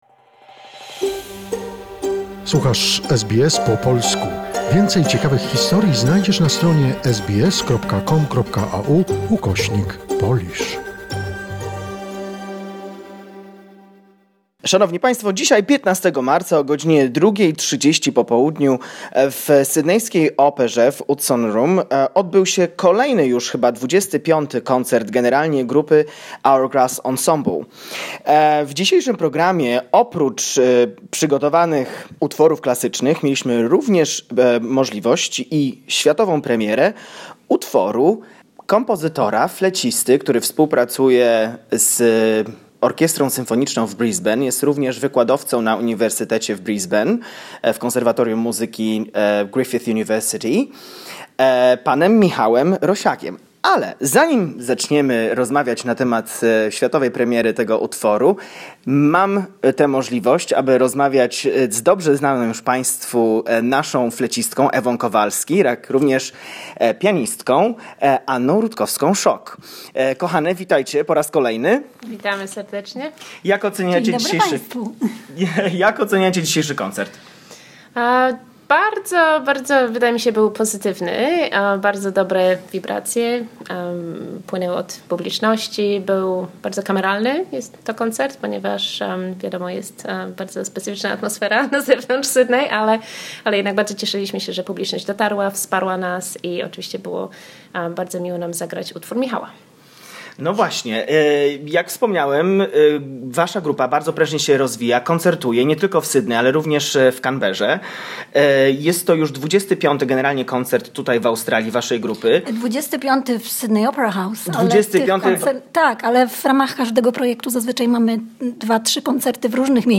talks to the musicians of the Hourglass Ensemble